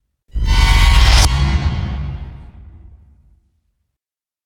Страшный звук, как из ужастика